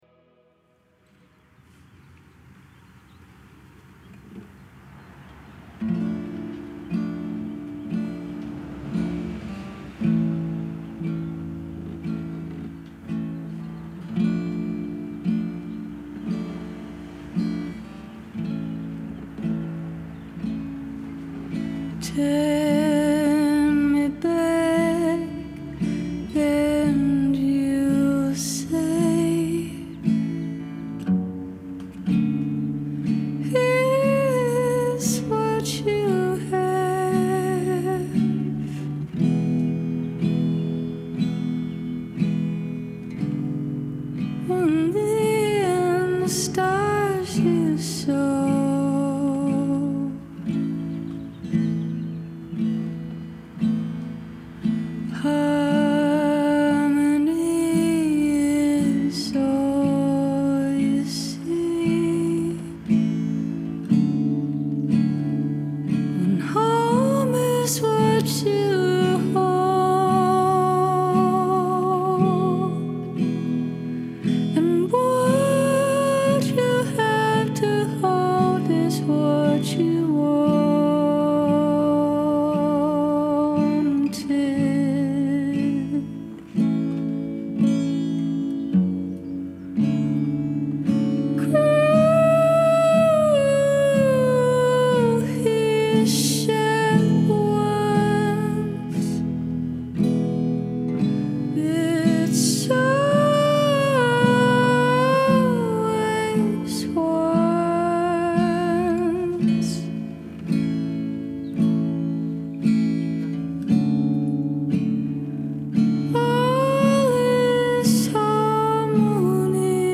Genre : Alternative, Indie, Pop, Rock